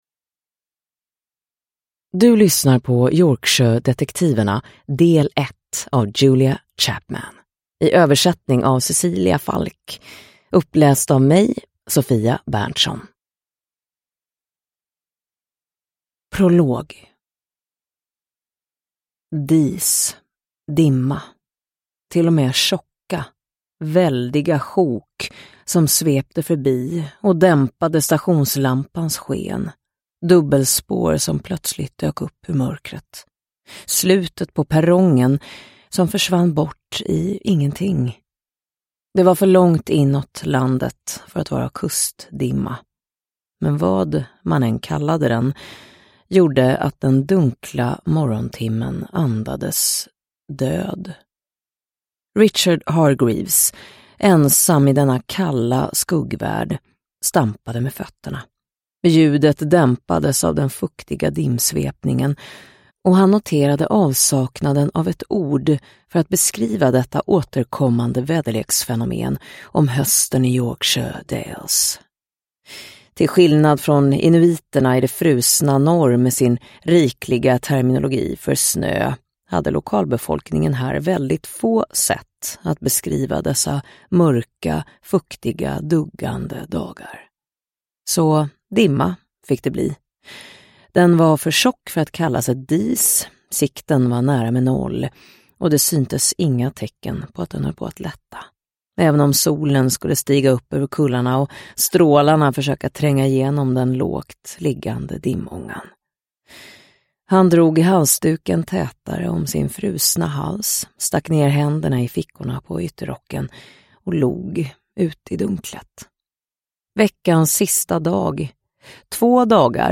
Dimma över heden – Ljudbok – Laddas ner